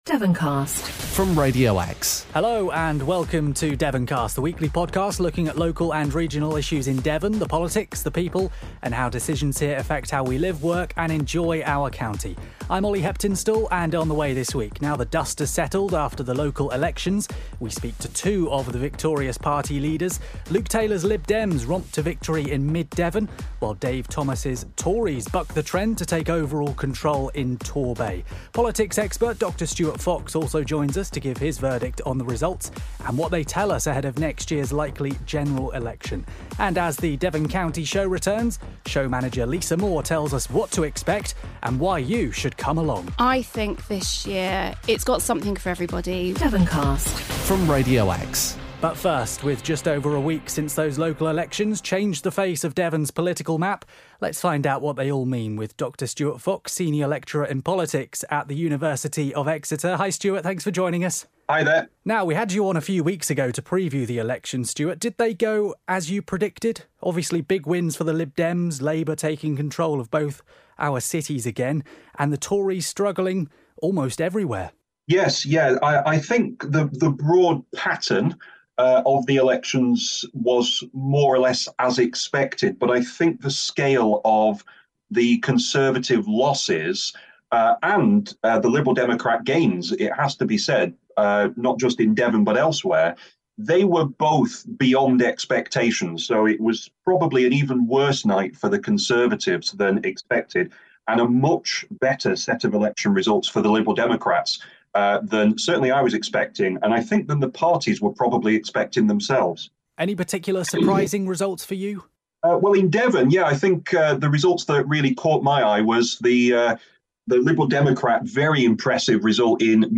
Saturday, 13 May 2023 10:33 By Radio Exe News Share on Facebook Share on Messenger Share on Messenger Share on X Share on Whatsapp Devoncast assesses the outcome The latest local democracy podcast from Radio Exe features a political expert gnawing through the outcome of last week's council elections.
We also hear from two of the victorious party leaders in Devon.